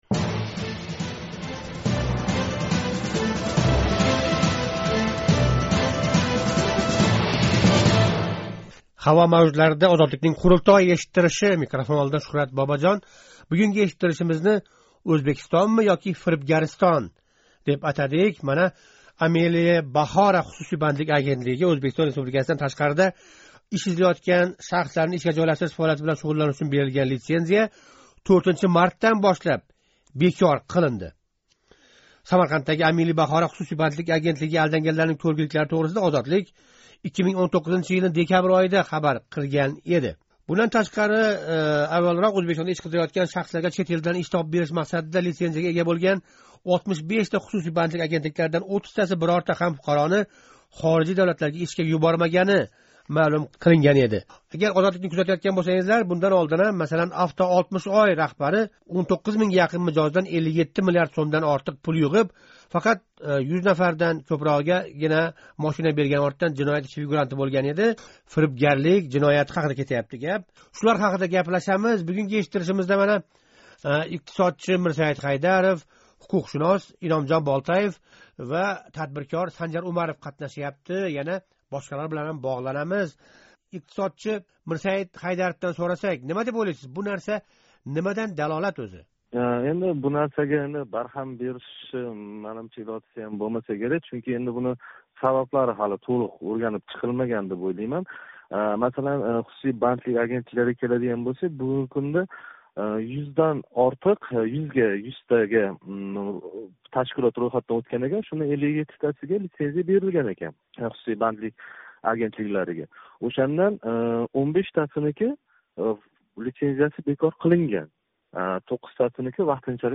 Озодликнинг “Қурултой” эшиттиришида қатнашганлар "Ўзбекистон “фирибгаристонга” айланмаслиги учун нима қилмоқ керак?" деган савол жавобини излади.